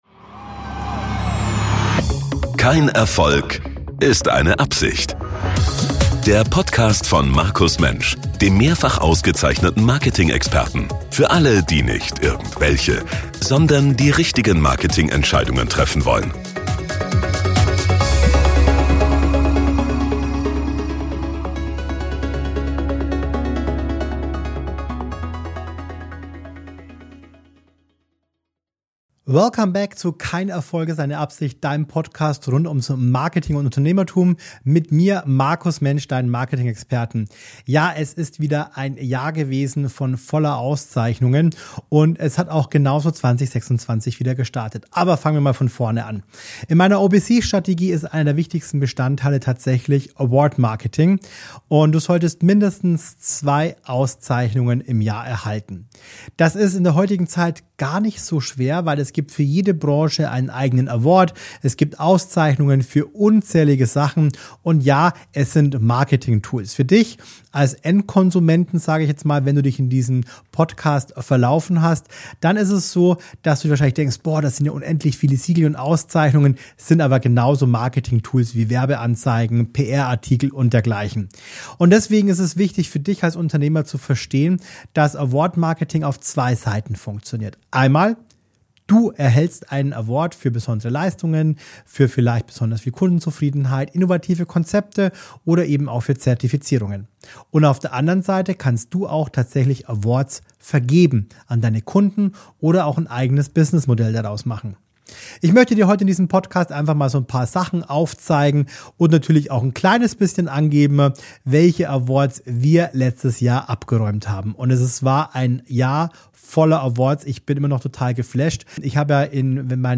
Solo-Folge